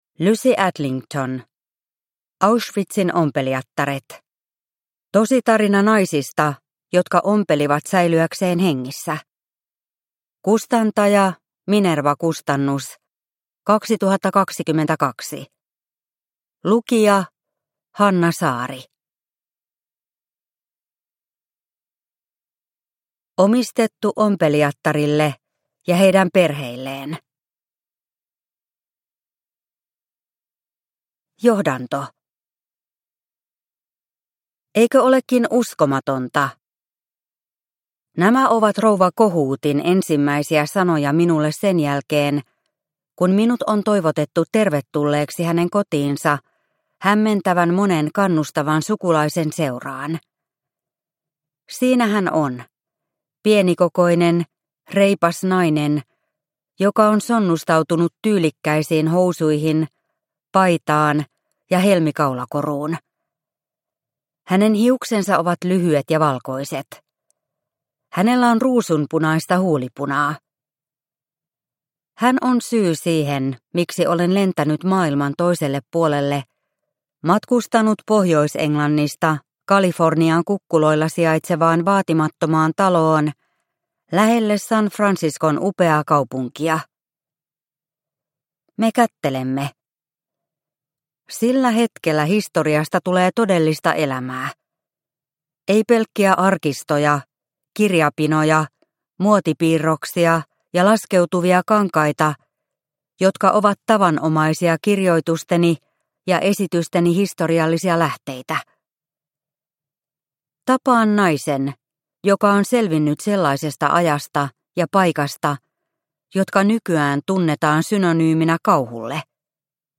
Auschwitzin ompelijattaret – Ljudbok – Laddas ner